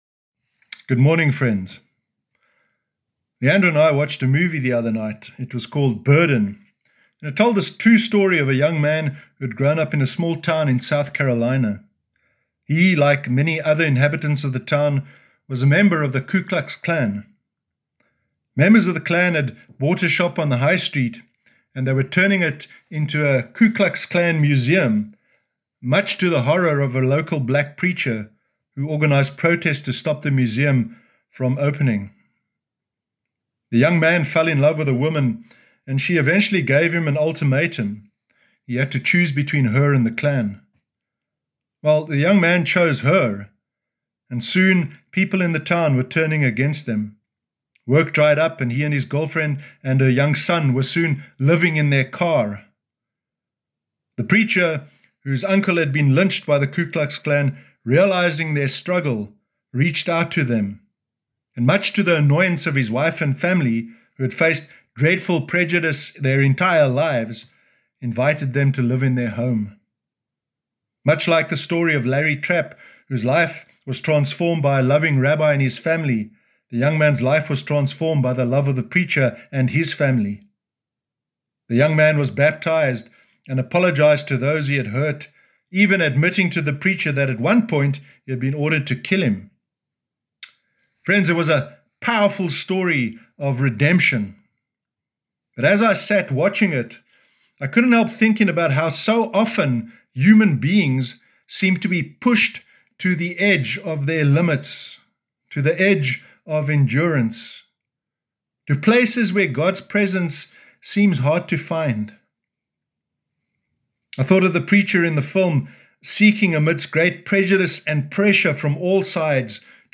Sermon Sunday 2 August 2020
sermon-sunday-2-august-2020.mp3